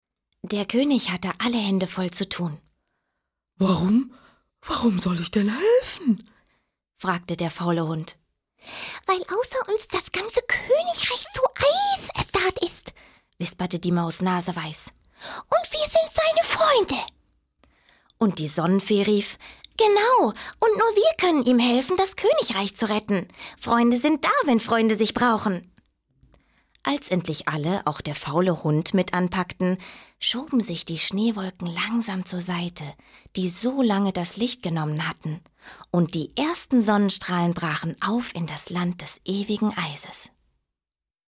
Image- und Industriefilme Stimme: variabel einsetzbar, mittel – hell, natürlich, lebendig, frisch, seriös, informativ, erklärend, motivierend, dynamisch, facettenreich, jugendlich, markant, weiblich, warm und weich, frech, edgy, kindlich
Sprechprobe: Sonstiges (Muttersprache):